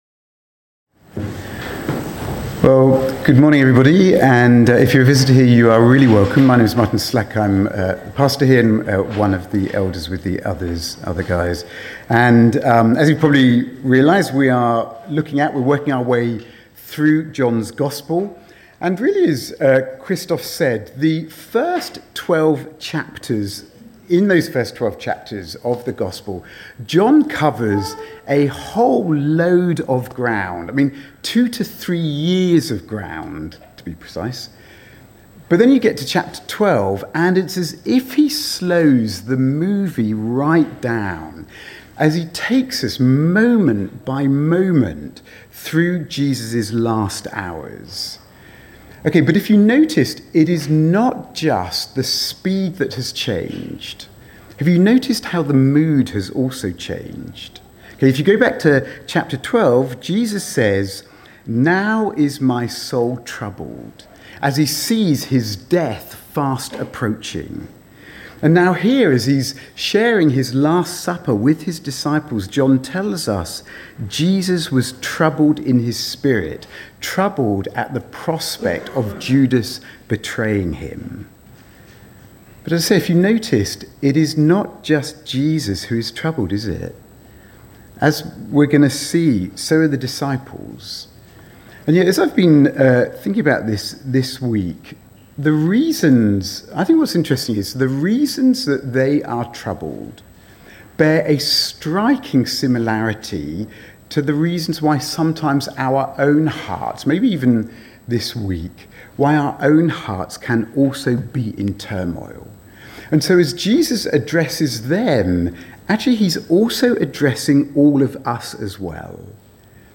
Sunday sermons from Westlake Church Lausanne, Switzerland
Sermons